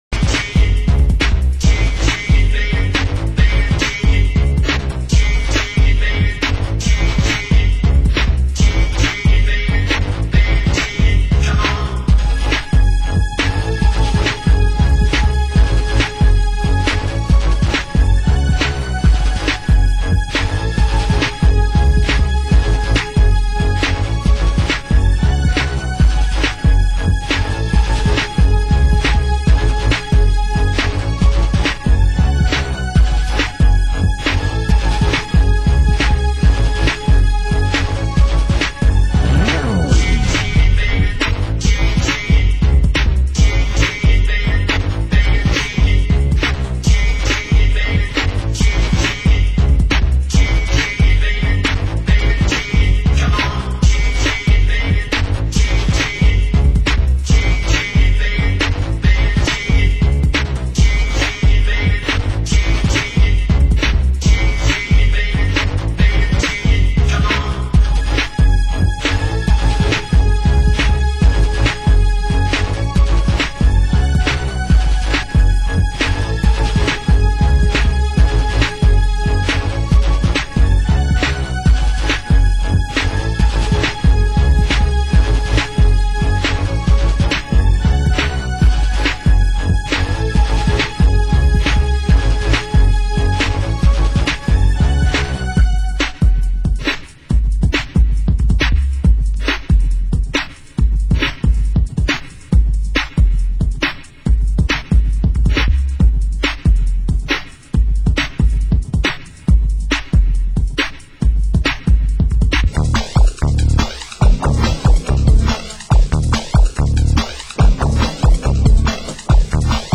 Genre: Ghetto Tech